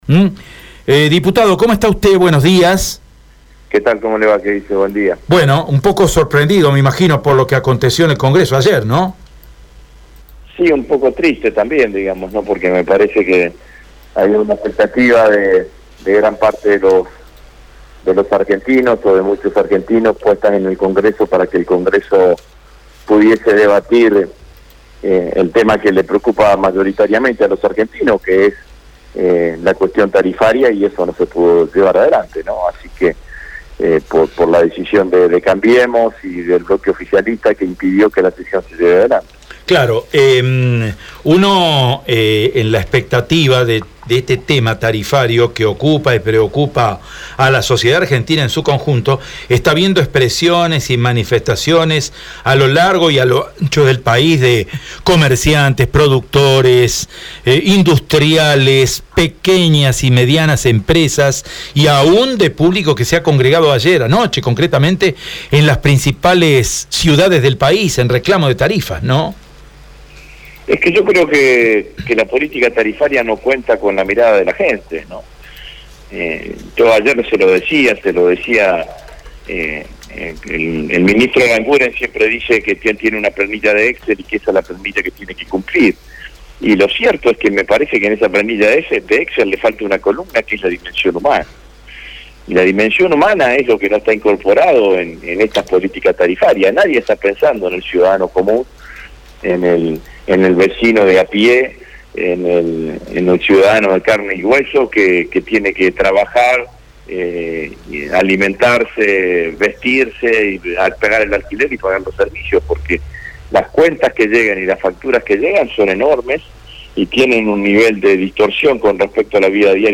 El legislador nacional por Santa Fe habló con Estilo EME sobre la sesión que no pudo darse ayer en el Congreso Nacional.
AUDIO ROSSI POR ESTILO EME: